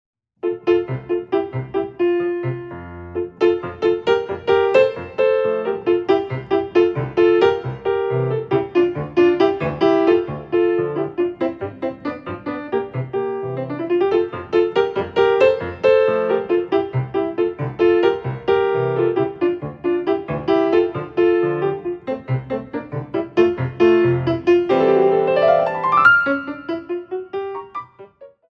16x8 6/8